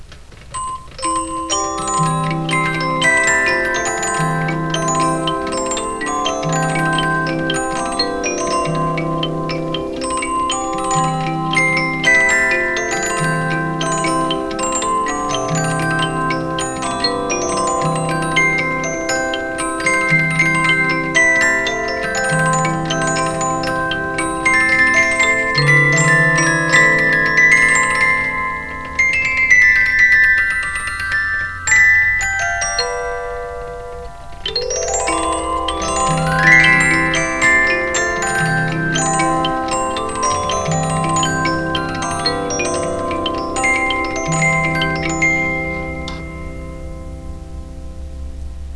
Criterion Music Box
I have a Criterion Disc Music Box.